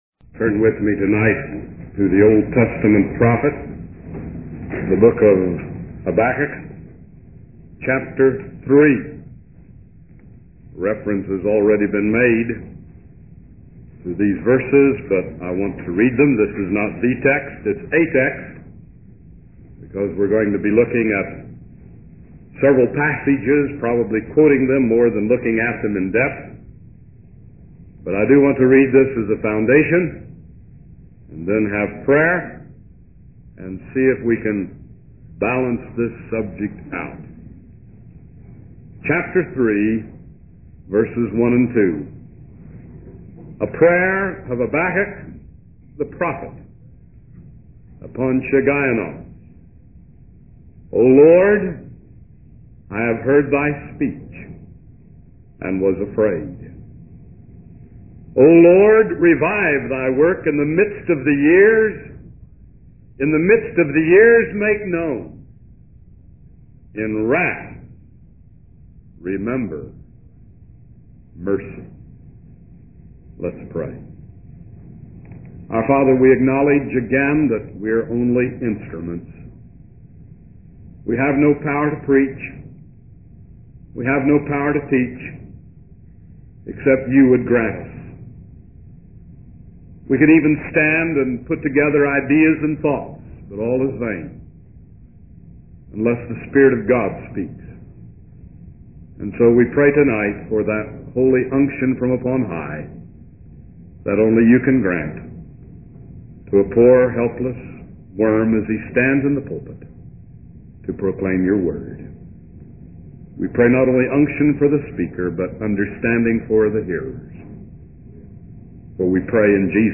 In this sermon, the speaker reflects on his family's reformed tradition and the blessings that America has received as a nation.